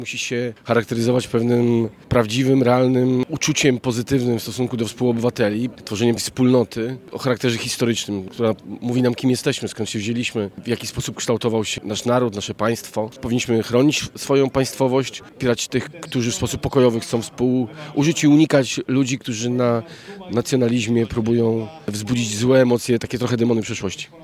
Przedstawiciele miasta, województwa, radni, parlamentarzyści, kombatanci i mieszkańcy Szczecina wzięli dziś udział w obchodach Święta Niepodległości na pl. Szarych Szeregów w Szczecinie.
Poseł Koalicji Obywatelskiej Sławomir Nitras mówi czym powinien charakteryzować się patriotyzm w obecnych czasach.